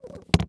TrunkClose.wav